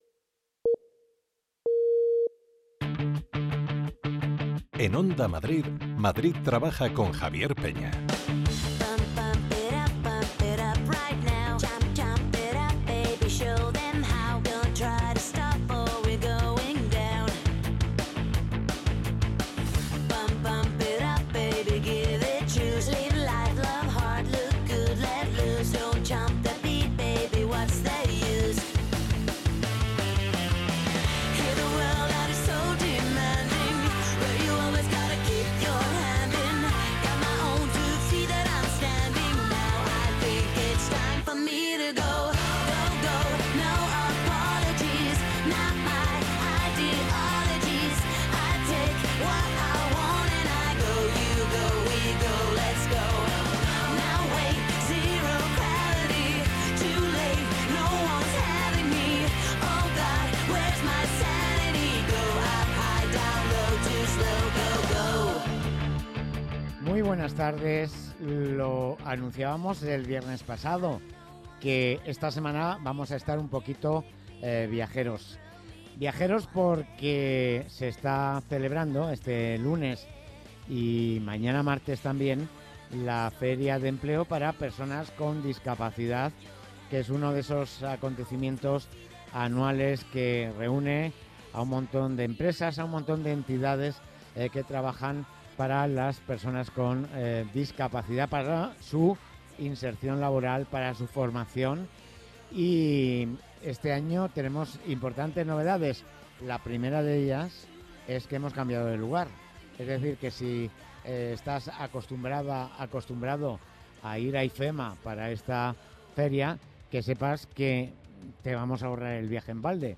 Hemos salido de los estudios de Onda Madrid y nos hemos ido a La Nave, en Villaverde. Allí se está celebrando la XV Feria de Empleo para personas con Discapacidad de la Comunidad de Madrid, que durará hasta mañana 12 de noviembre.